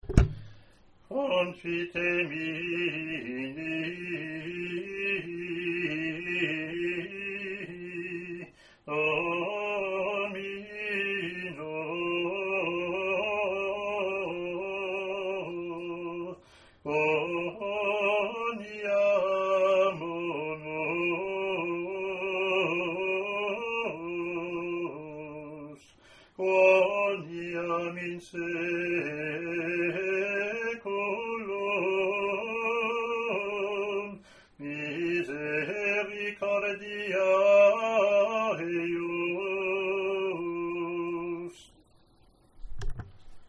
Rehearsal files for Gradual and Tract (Extraordinary Form):
Gradual verse